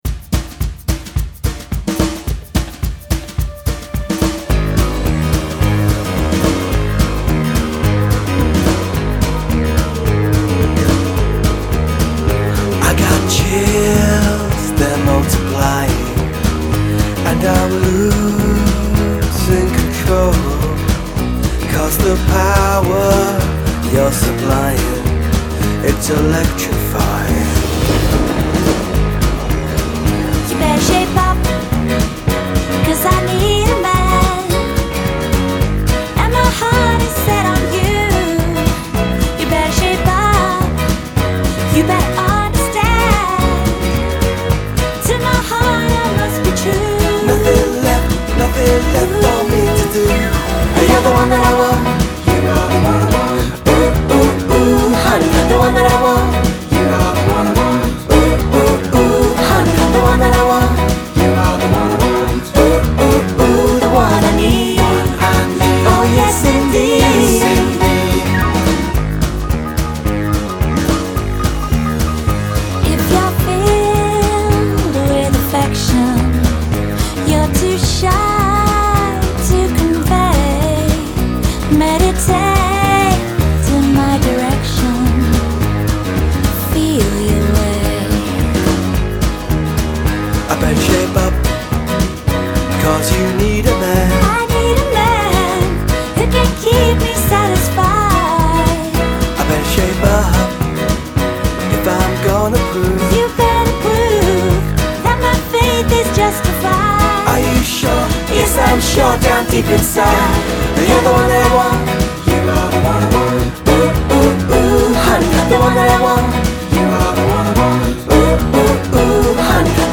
does some really great pop.